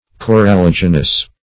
Search Result for " coralligenous" : The Collaborative International Dictionary of English v.0.48: Coralligenous \Cor`al*lig"e*nous\, a. producing coral; coralligerous; coralliferous.